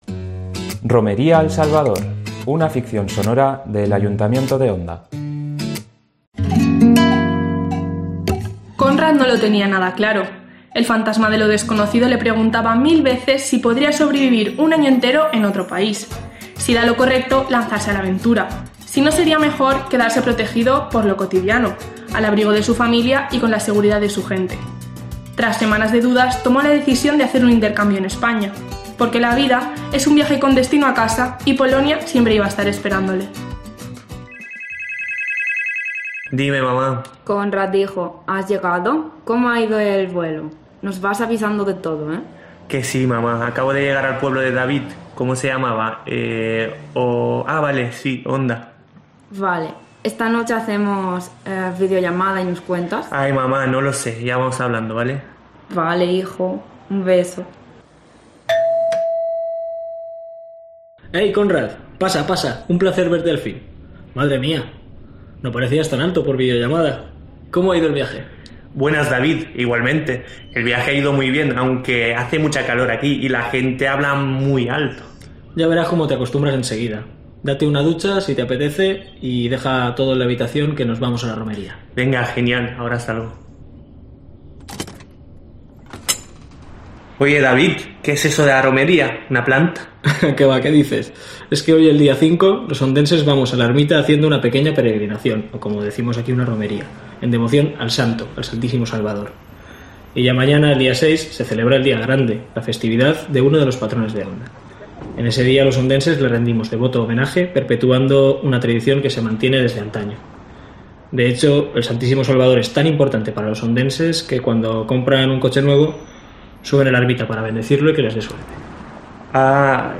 Ficción sonora de la romería a El Salvador de Onda